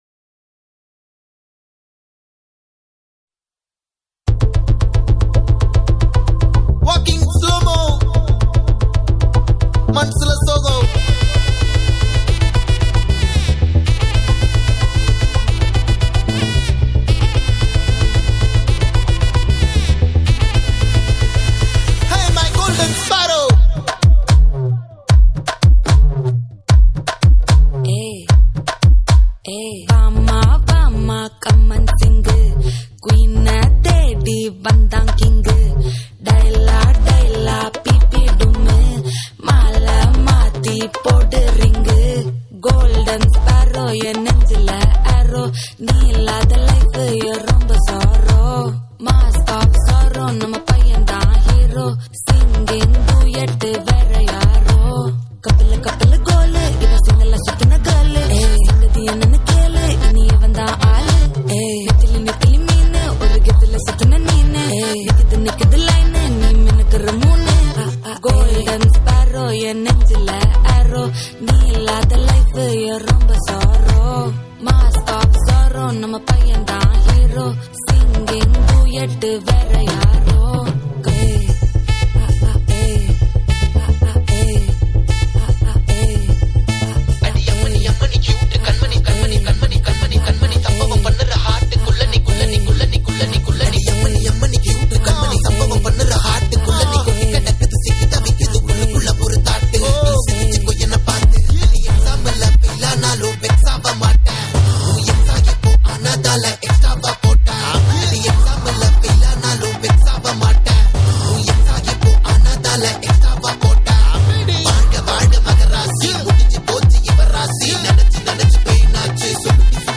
ALL TAMIL LOVE DJ REMIX